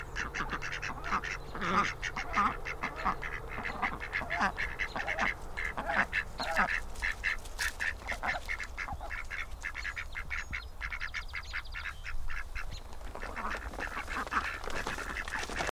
Quacking Ducks
描述：Amid the din of my neighbor's barking dog, passing traffic and singing birds, you can hear the quacking of a large flock of ducks in the quiet river outside my kitchen window (I don't know the species to this day). I used the builtin microphone of my mini Lenovo laptop to record this.
标签： quacking quackingduck quackingducks duck ducks quack
声道立体声